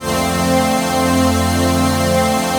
DM PAD2-40.wav